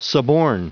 Prononciation du mot suborn en anglais (fichier audio)